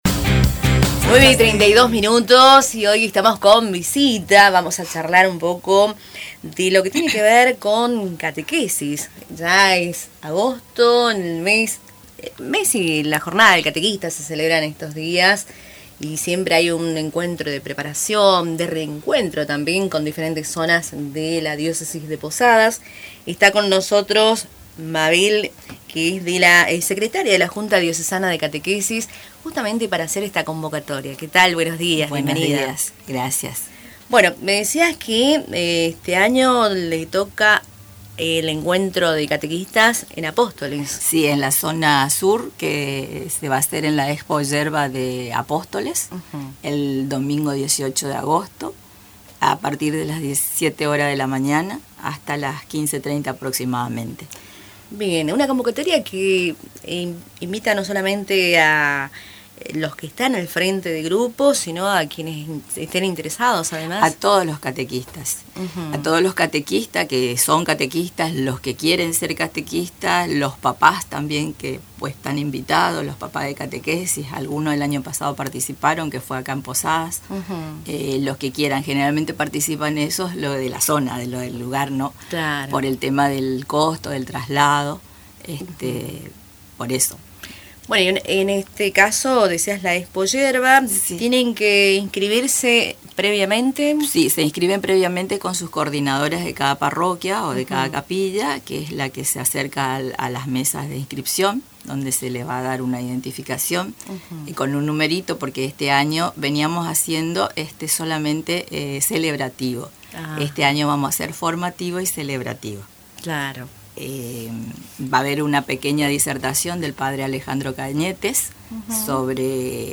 En los estudios de Radio Tupa Mbae